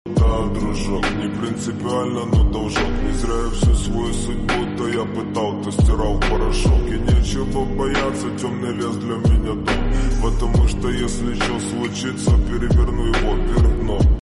Exhaust W220 S550!